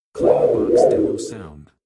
“K.I.T.T. Scanner” Clamor Sound Effect
Can also be used as a car sound and works as a Tesla LockChime sound for the Boombox.
Kitt-scanner-demo.wav